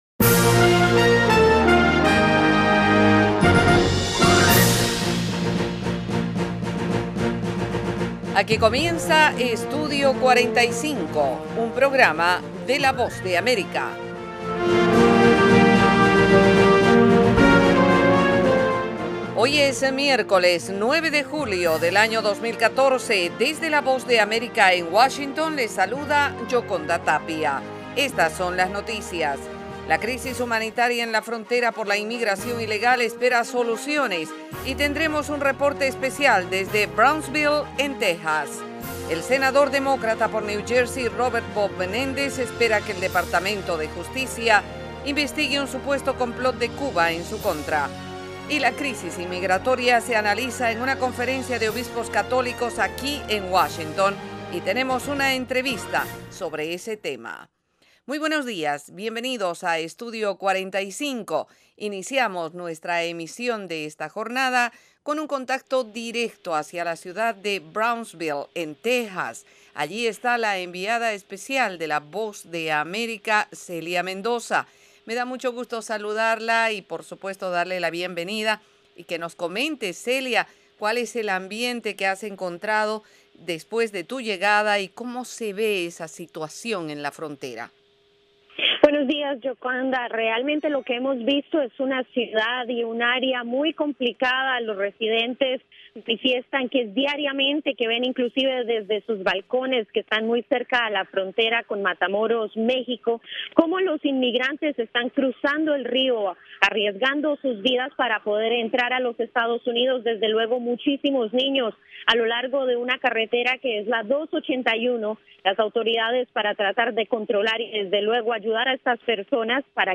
El programa ofrece -en 30 minutos- la actualidad noticiosa de Estados Unidos con el acontecer más relevante en América Latina y el resto del mundo.